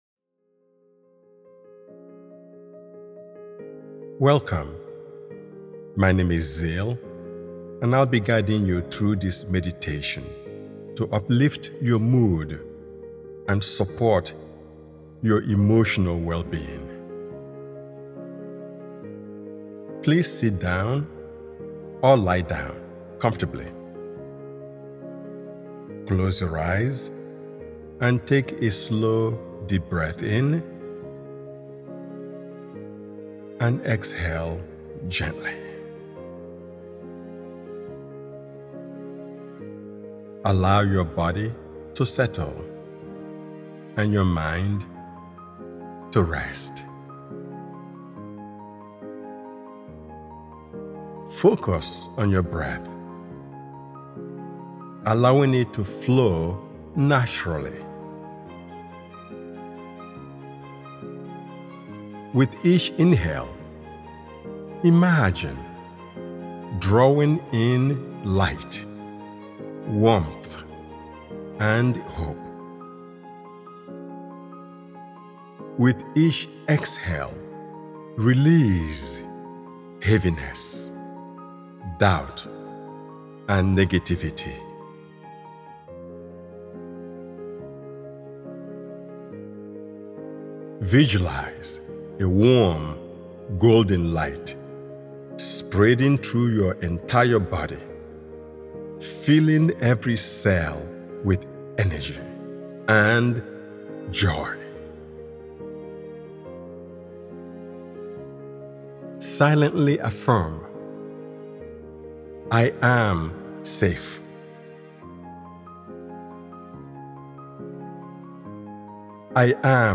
Uplift Mood & Overcome Depression is a supportive guided meditation designed to gently lift your spirit and restore emotional balance.